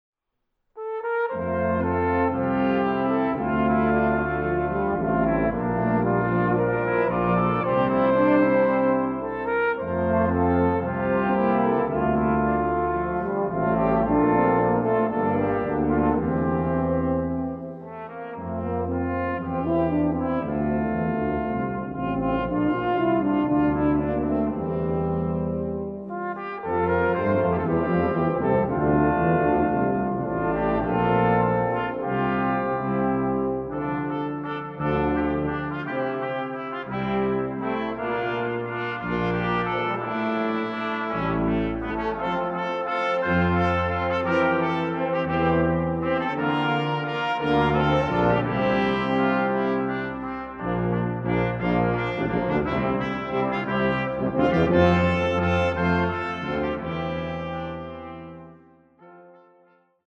Musik für Posaunenchor und Blechbläserensemble